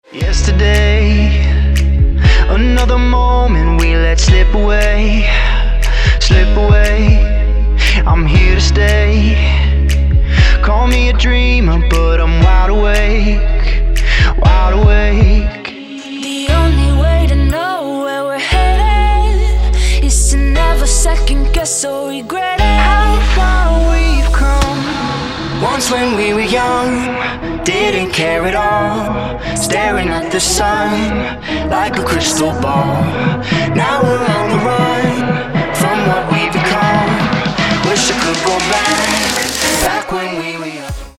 • Качество: 192, Stereo
поп
dance
EDM
Melodic
vocal